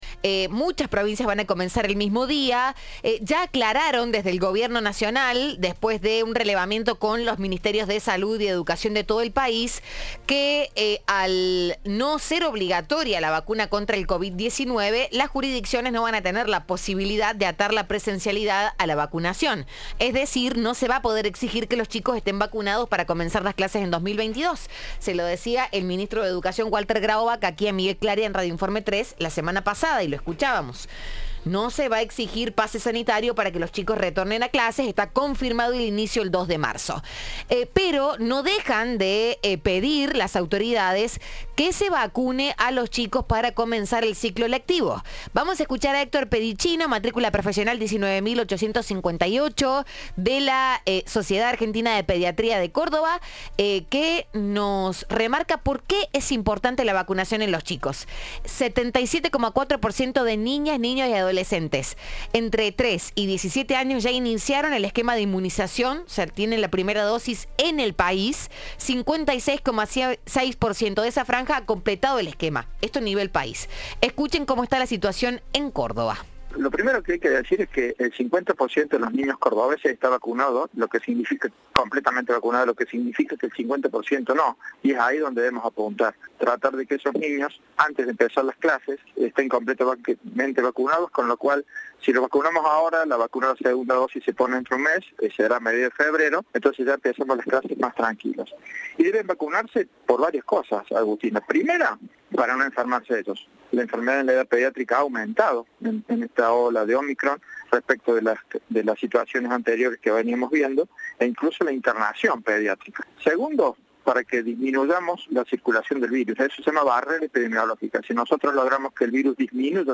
En diálogo con Cadena 3